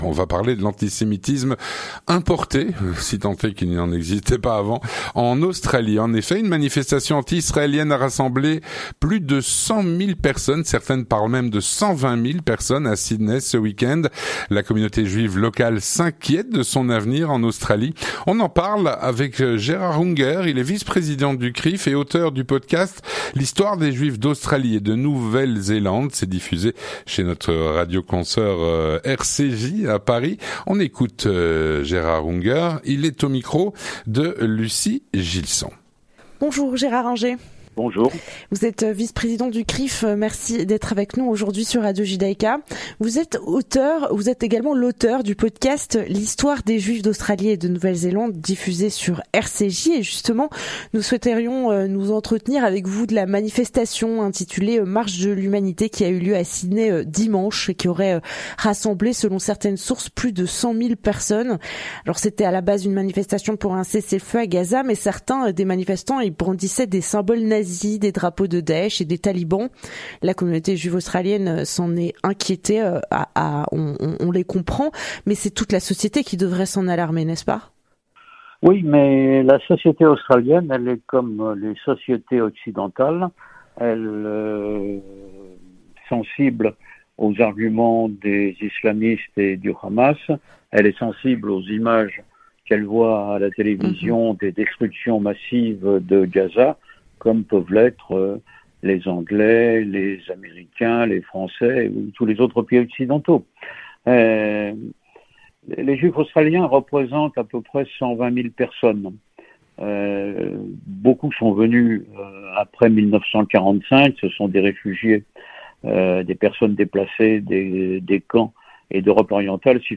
L'entretien du 18H - L’antisémitisme importé en Australie.